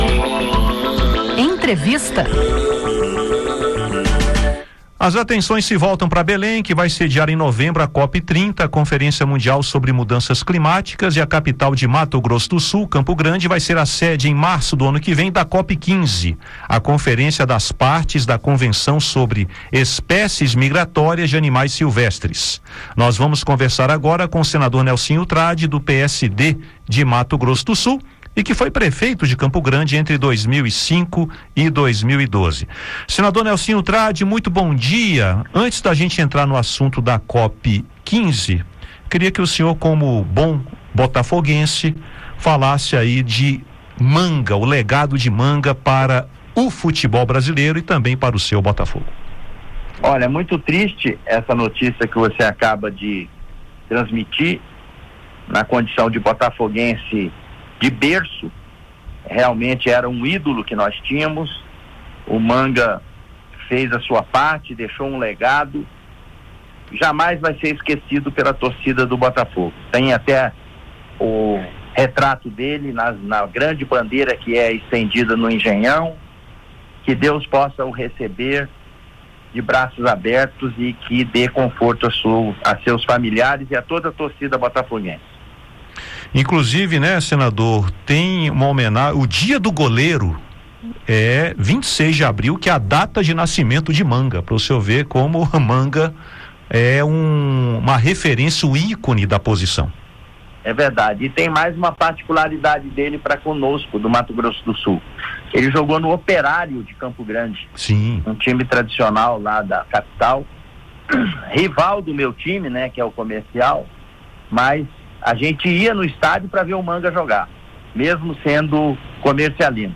O senador Nelsinho Trad (PSD-MS), que foi prefeito de Campo Grande entre 2005 e 2012, fala sobre a importância das conferências na trajetória de defesa ambiental e das soluções e políticas de governo. Ele destaca o que vai ser discutido na COP 15 e a importância do encontro para a preservação de espécies migratórias no Pantanal e região.